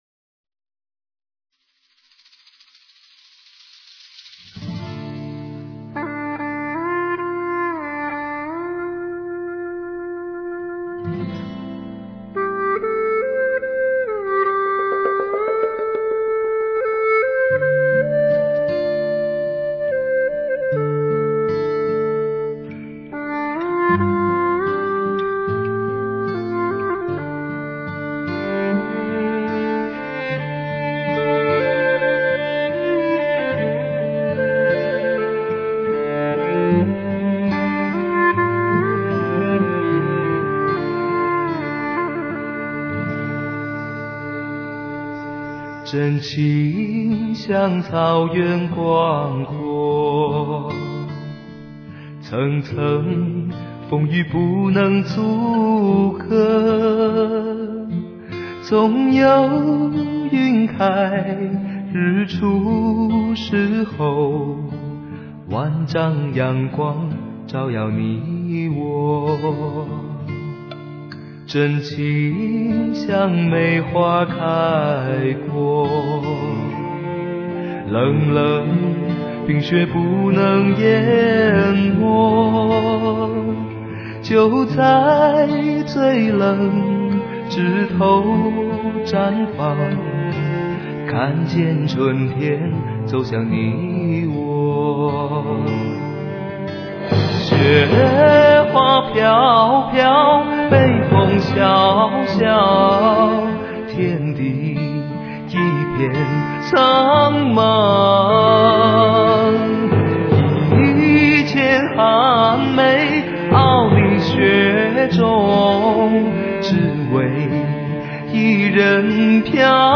细微处醇美厚实，激昂处丝丝入扣，编曲、录音、和声、配器都相得益彰，